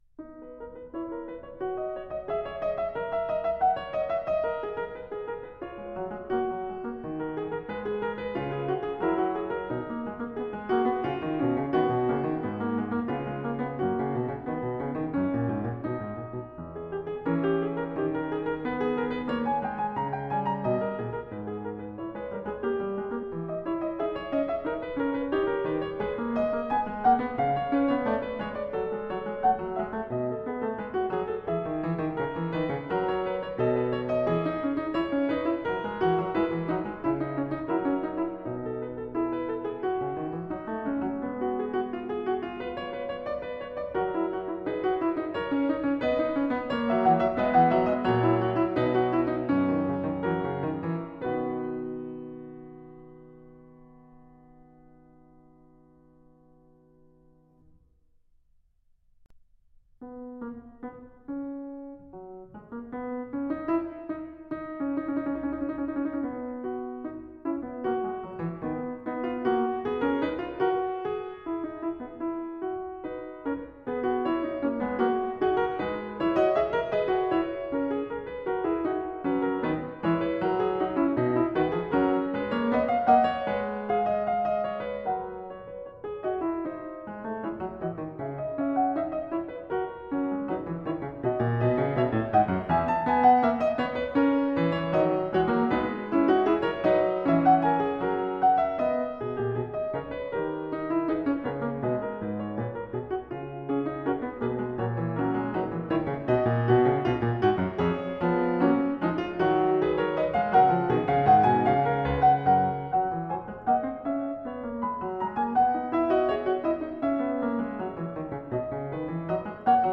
Piano  (View more Advanced Piano Music)
Classical (View more Classical Piano Music)
piano